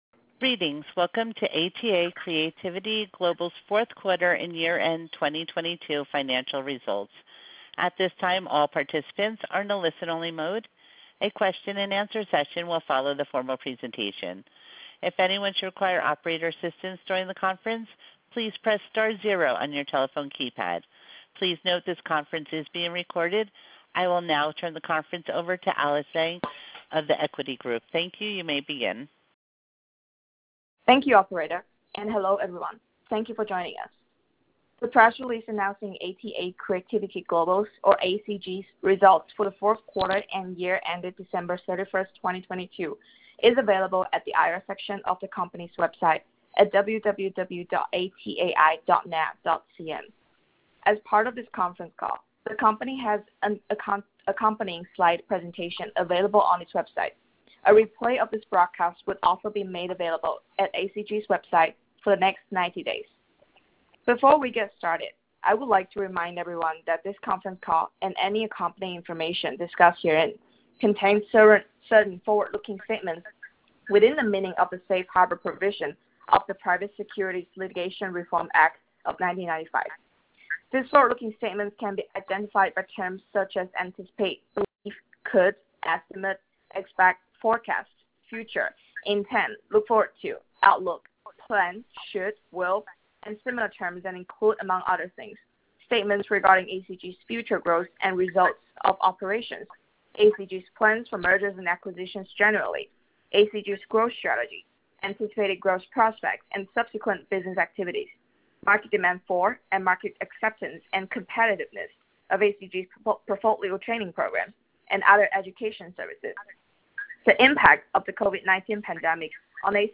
Earnings Webcast FY 2022 Audio